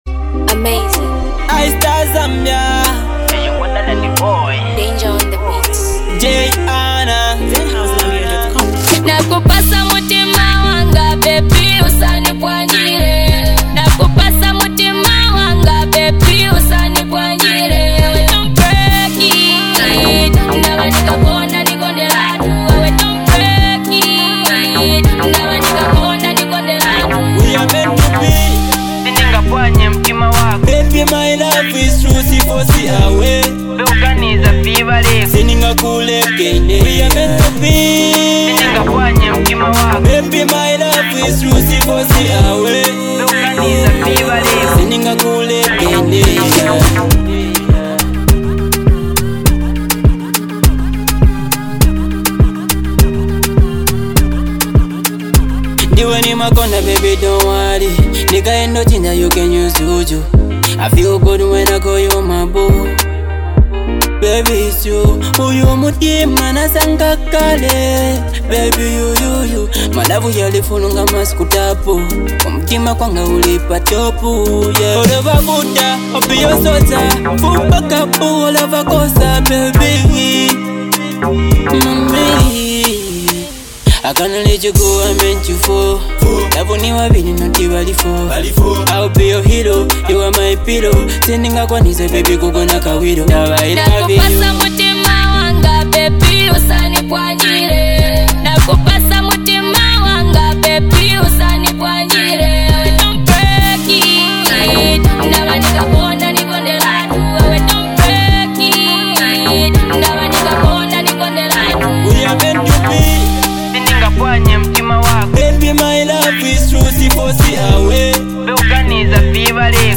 powerful vocals from both artists